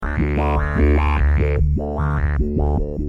Oberheim - Matrix 1000 11